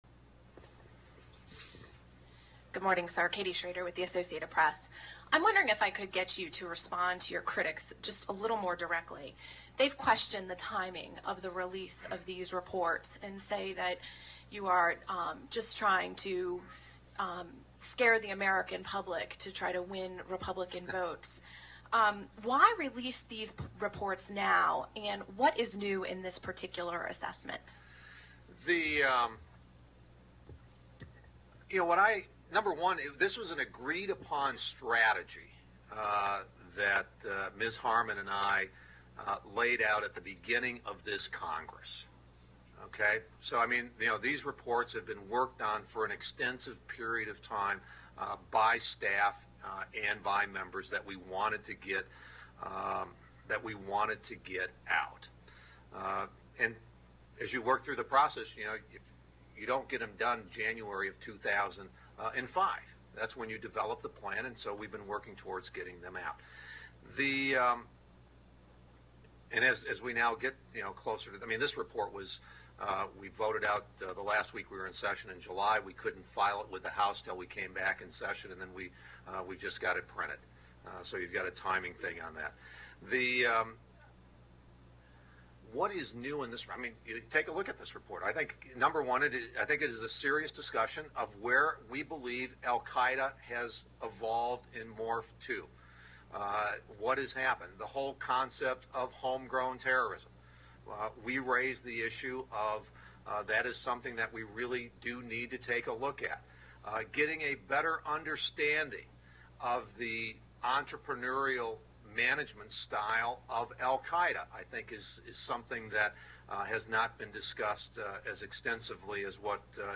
Reporters Q & A